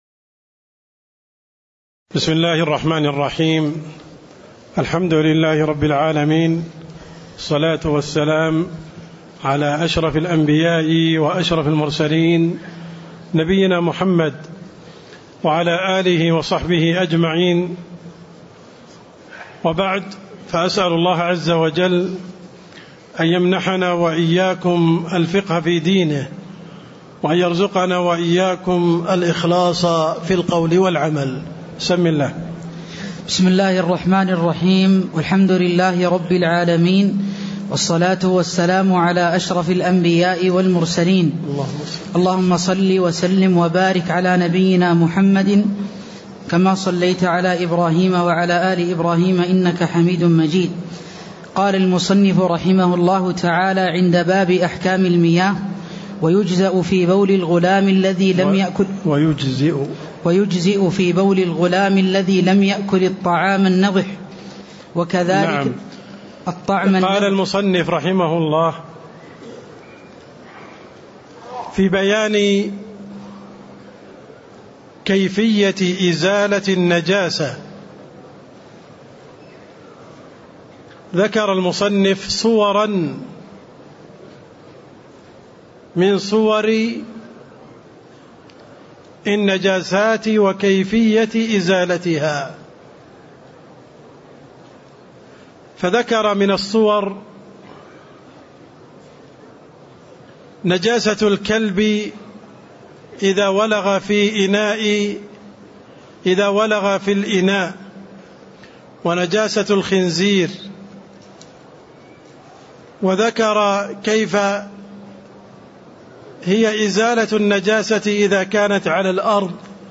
تاريخ النشر ٣ جمادى الأولى ١٤٣٥ هـ المكان: المسجد النبوي الشيخ: عبدالرحمن السند عبدالرحمن السند باب أحكام المياة (03)